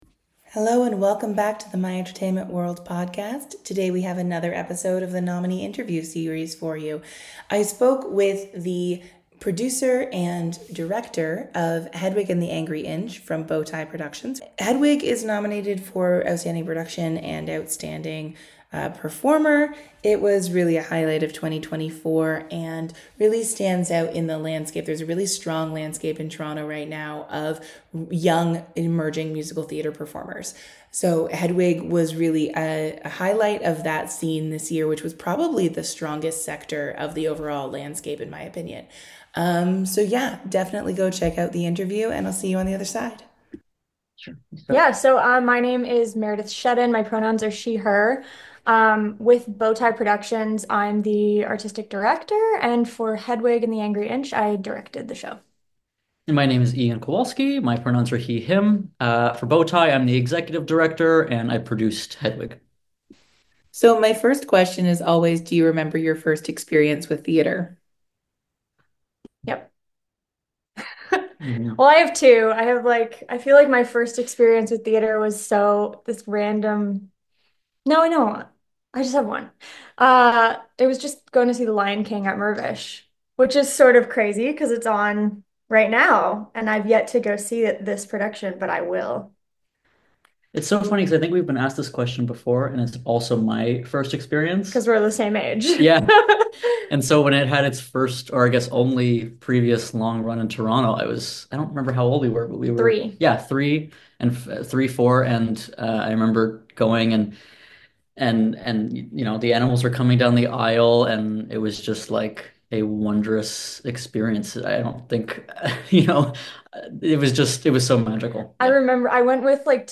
Nominee Interview Series: Bowtie Productions